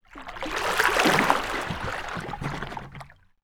Water_45.wav